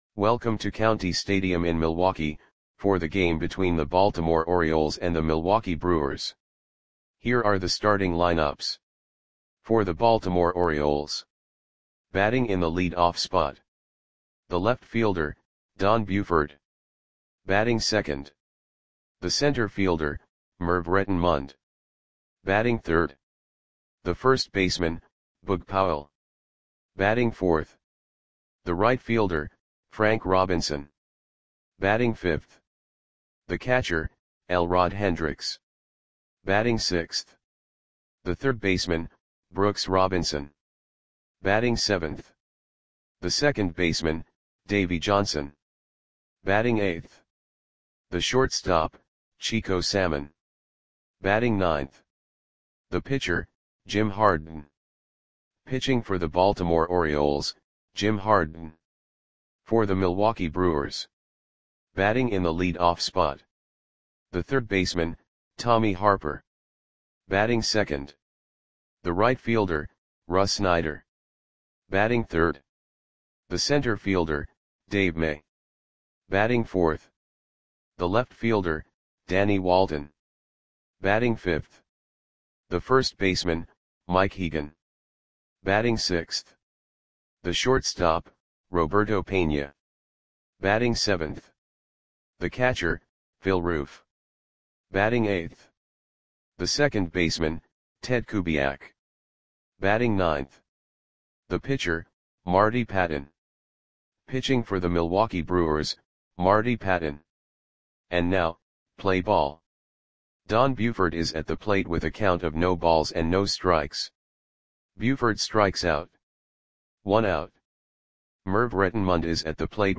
Audio Play-by-Play for Milwaukee Brewers on August 18, 1970
Click the button below to listen to the audio play-by-play.